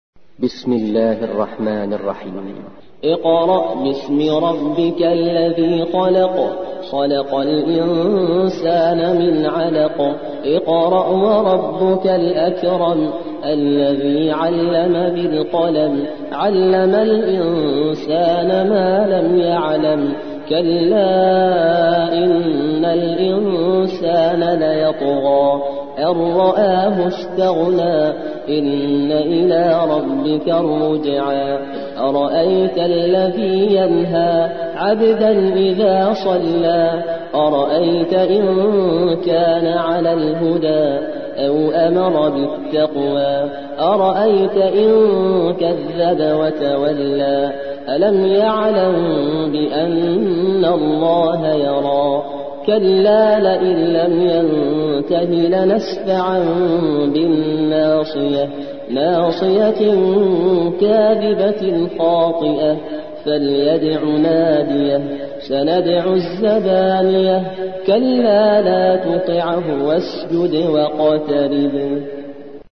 96. سورة العلق / القارئ